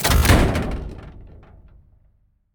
reload2.ogg